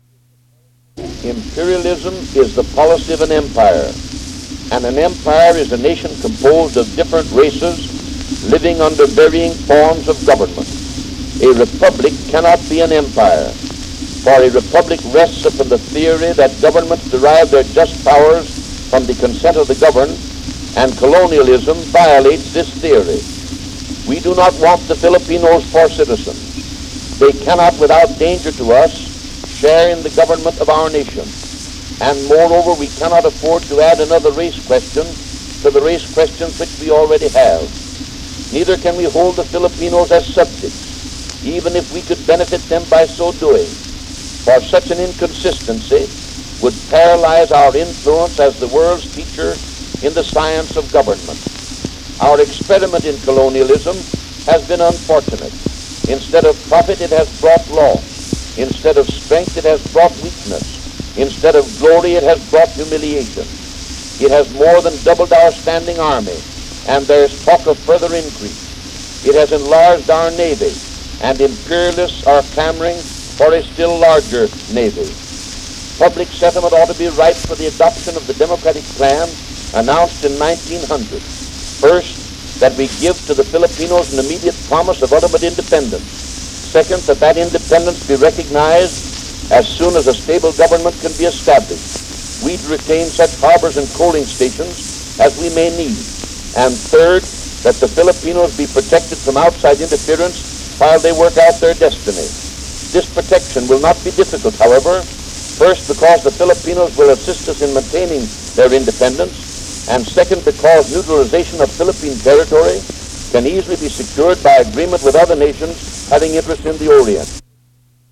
Speakers Bryan, William Jennings, 1860-1925
Recorded by Edison, 1901.